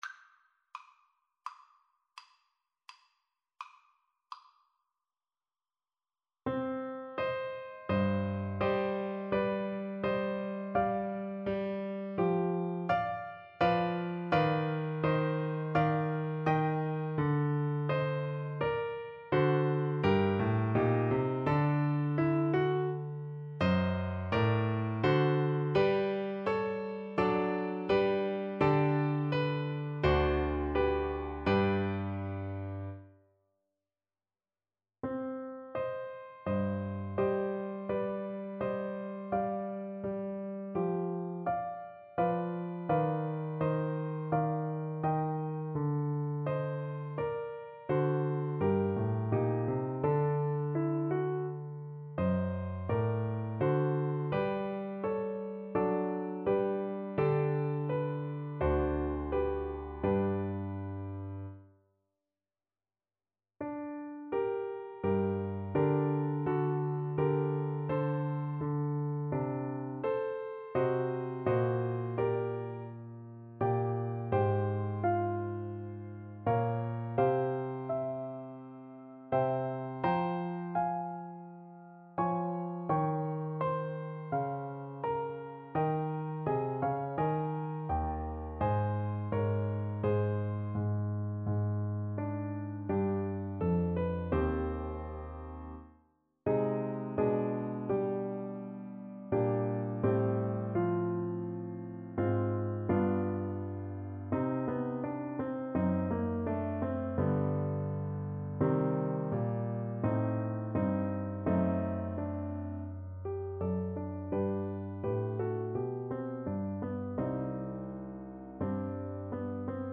Free Sheet music for Trumpet
Trumpet
C minor (Sounding Pitch) D minor (Trumpet in Bb) (View more C minor Music for Trumpet )
Largo =42
4/4 (View more 4/4 Music)